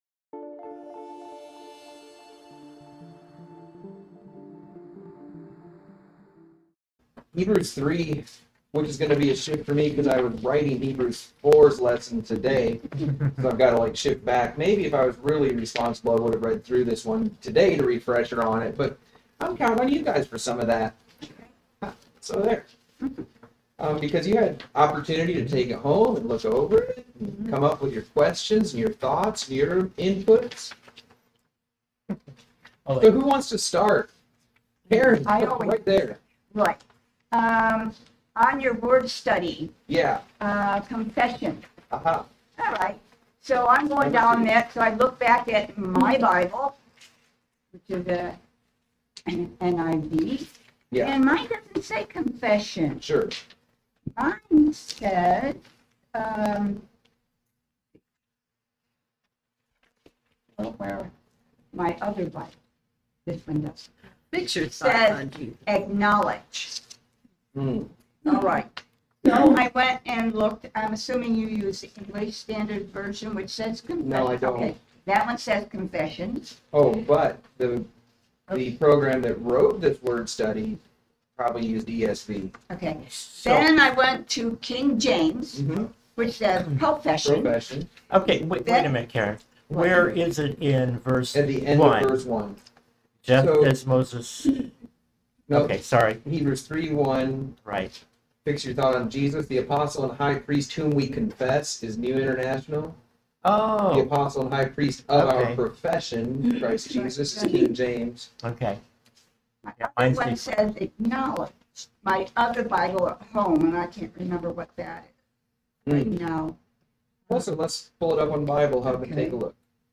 Class Audio: Hebrews ch #3 from Pastor's Bible Study on Wednesdays